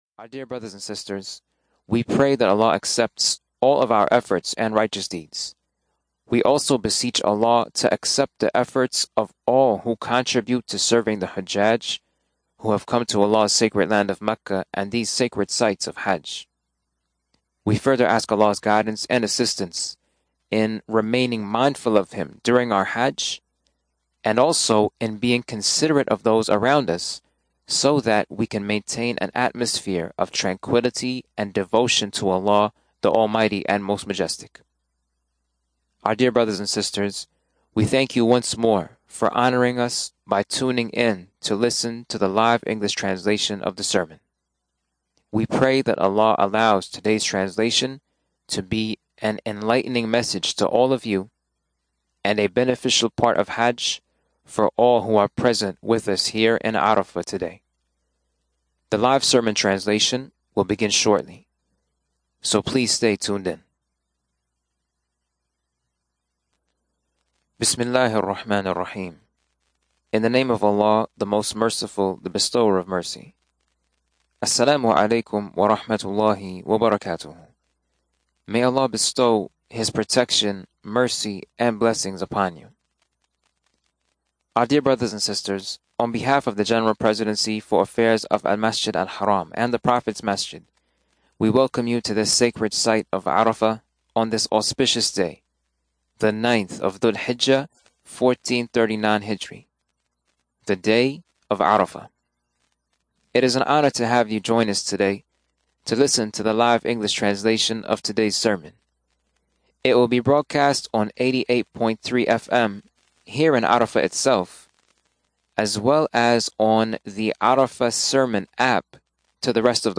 Sermon from 'Arafah (Hajj 1439)